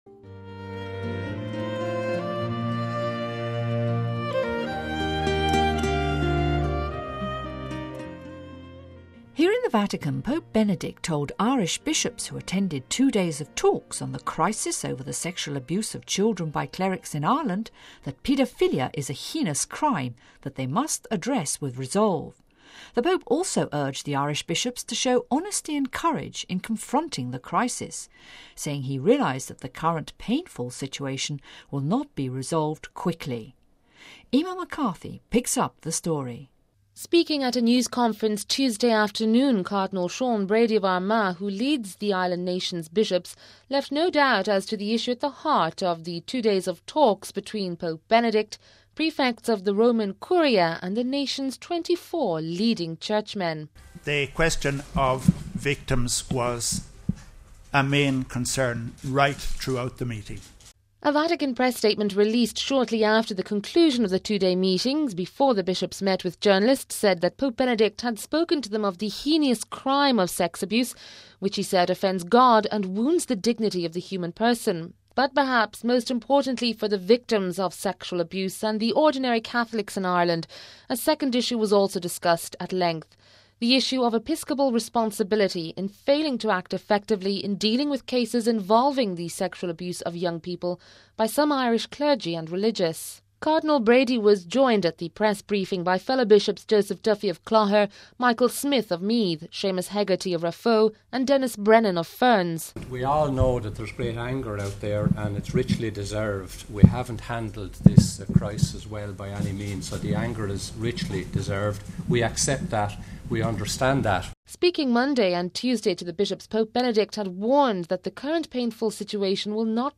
We have a full report on the conclusion of the two day meeting between Pope Benedict and Ireland's Catholic bishops dealing with the crisis over the sexual abuse of children by clerics and religious......